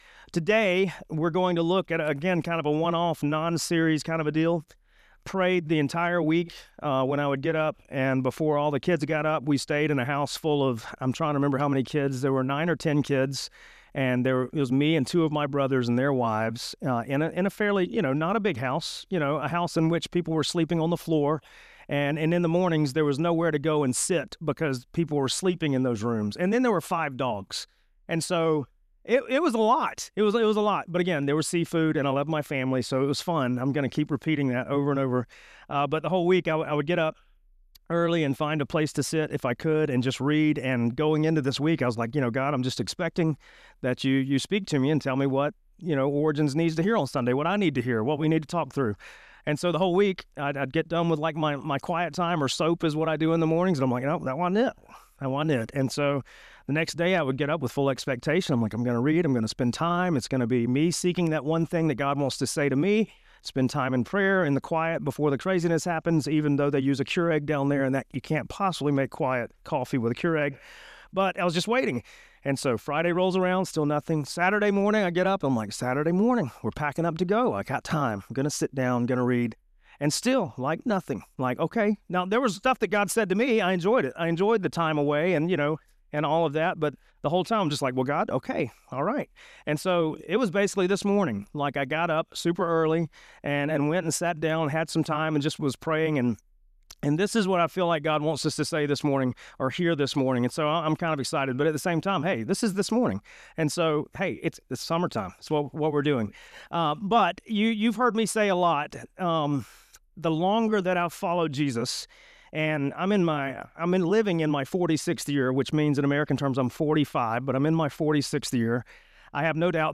Sermons from our Sunday Worship Gatherings